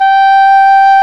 Index of /90_sSampleCDs/Roland L-CDX-03 Disk 1/CMB_Wind Sects 1/CMB_Wind Sect 2
WND ENGHRN0G.wav